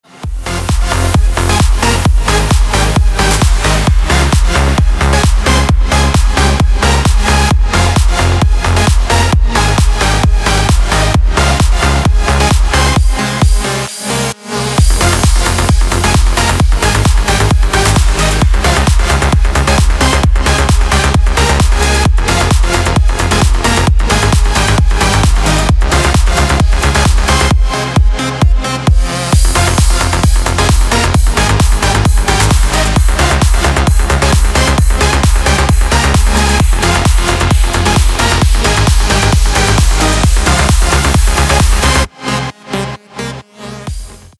• Качество: 320, Stereo
громкие
dance
Electronic
EDM
электронная музыка
без слов
Trance